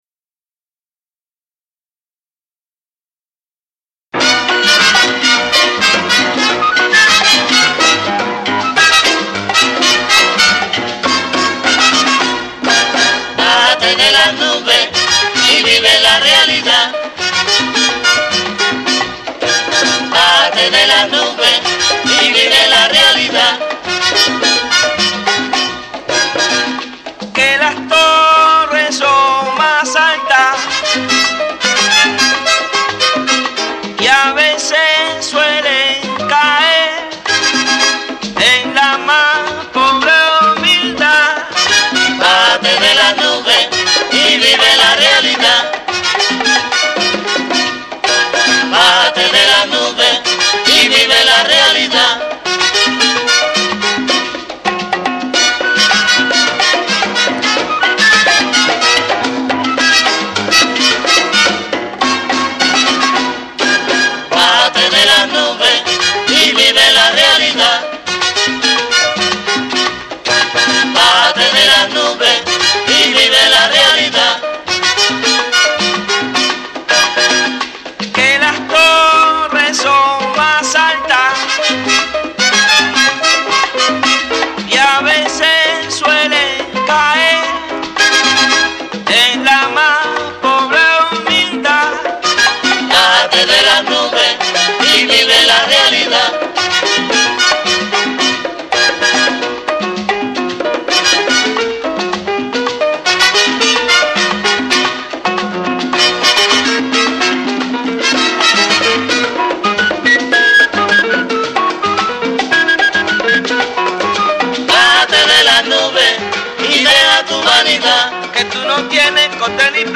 guaracha